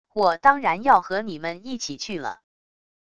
我当然要和你们一起去了wav音频生成系统WAV Audio Player